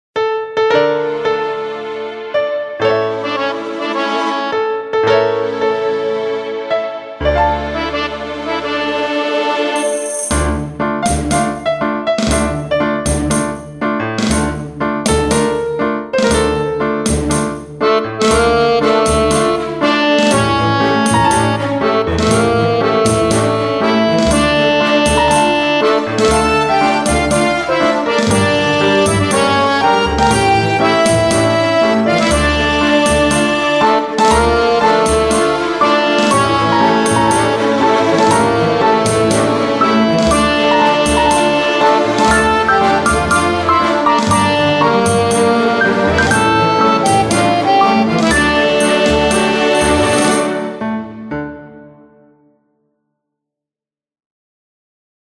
Folk song, U.S.A.
Tango Version, 0:54